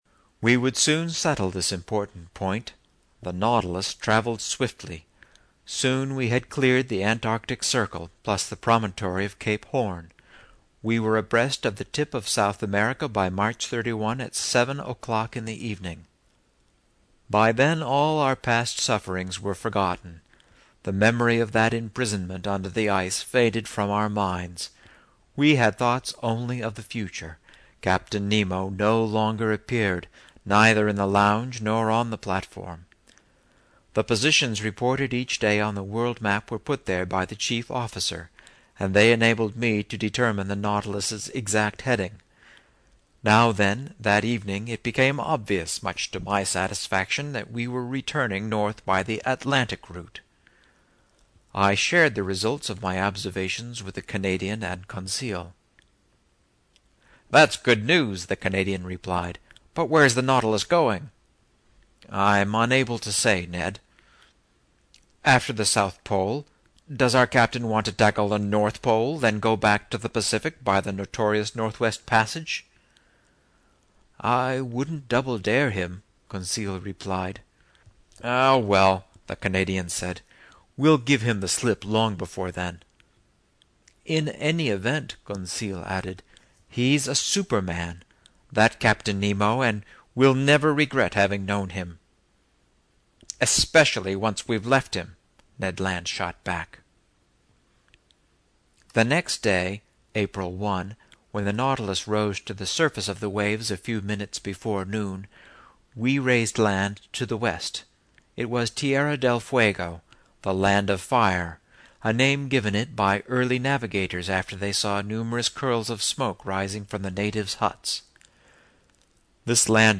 在线英语听力室英语听书《海底两万里》第480期 第30章 从合恩角到亚马逊河(3)的听力文件下载,《海底两万里》中英双语有声读物附MP3下载